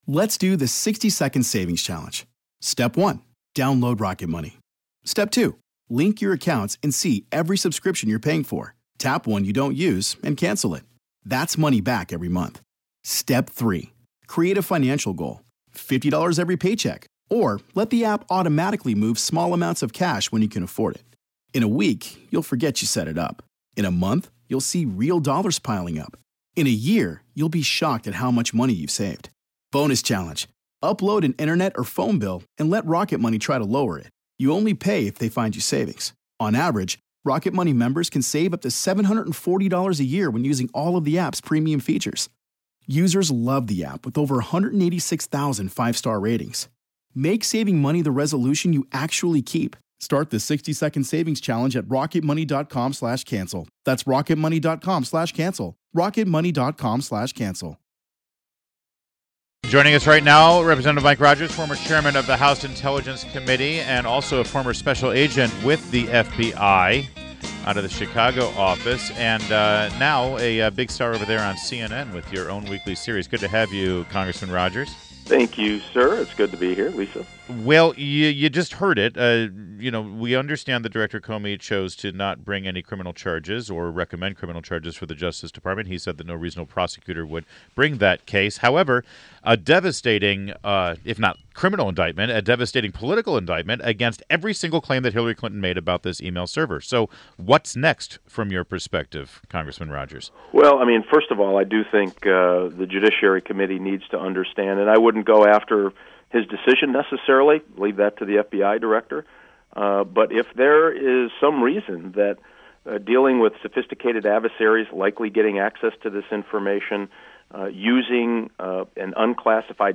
WMAL Interview Rep. Mike Rogers 07.06.16